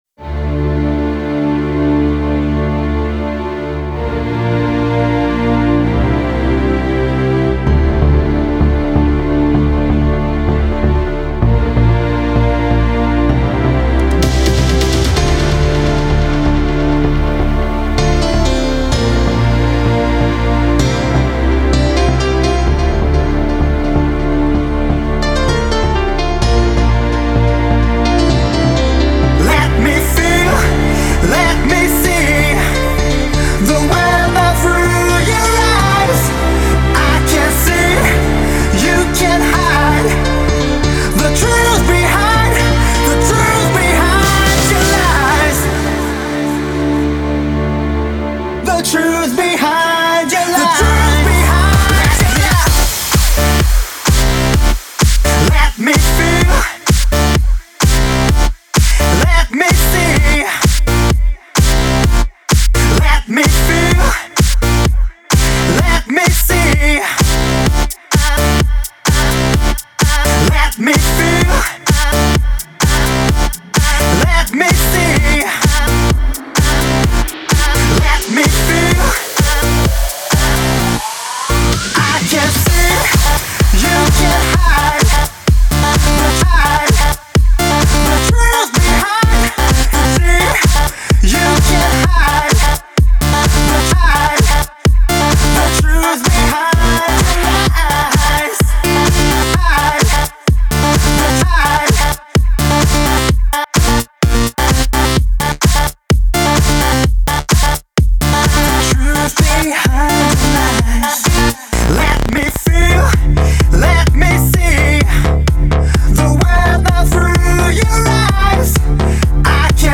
Категория: Клубная музыка